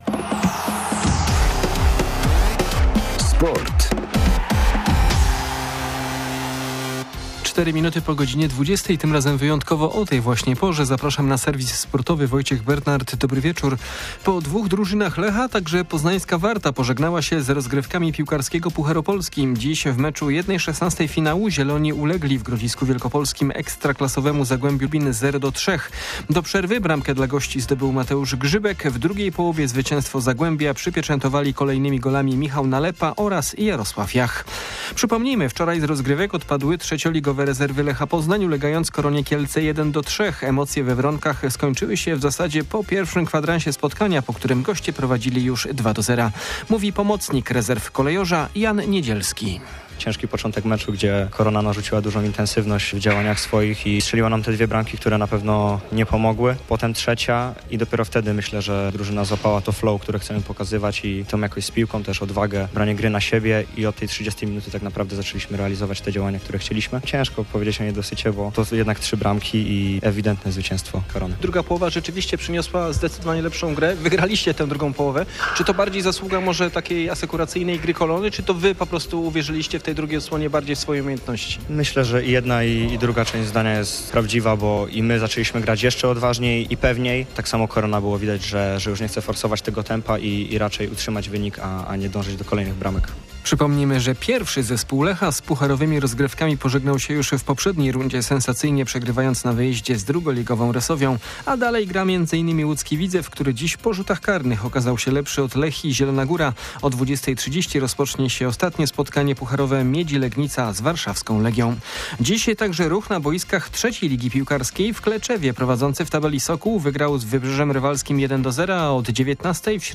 31.10.2024 SERWIS SPORTOWY GODZ. 20:05